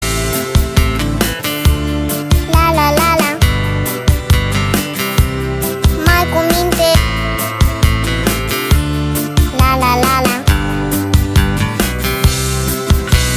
• Качество: 320, Stereo
детский голос
детская песенка